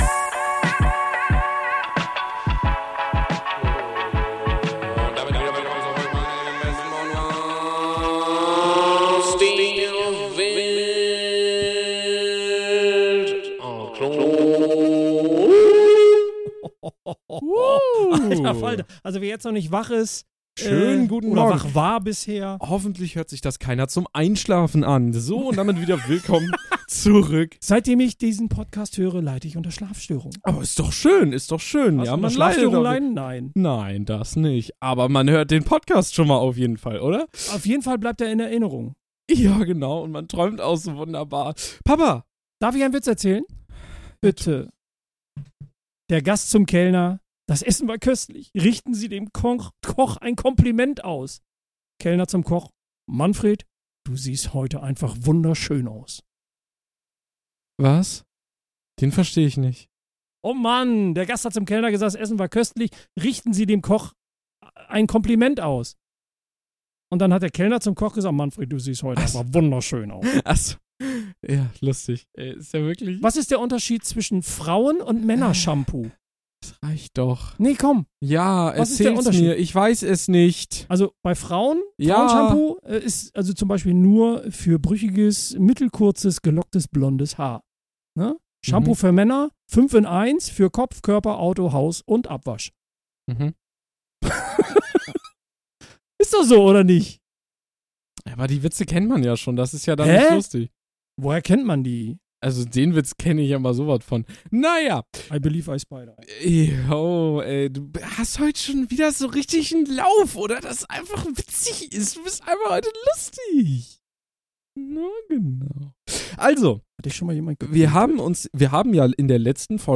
~ Alt/Klug: Der Vater/Sohn-Podcast Podcast